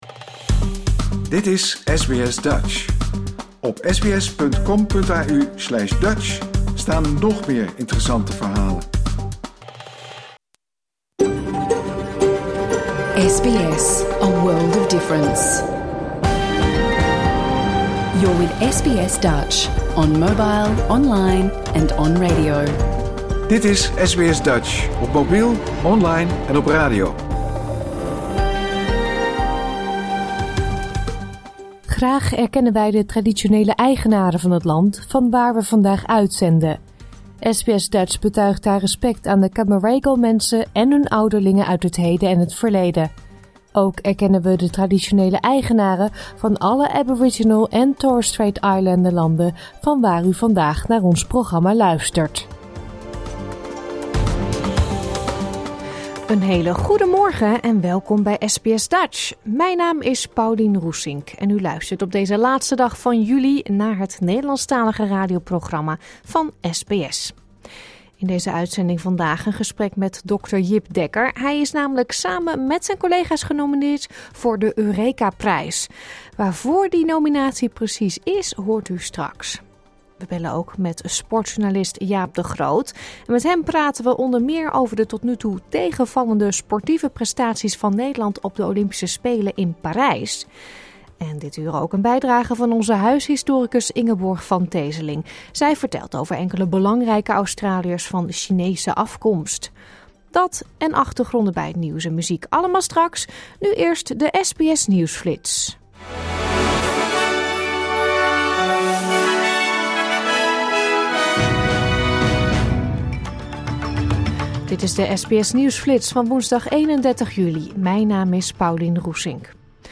Luister hier de uitzending van woensdag 31 juli 2024 (bijna) integraal terug.